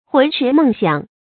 魂驰梦想 hún chí mèng xiǎng
魂驰梦想发音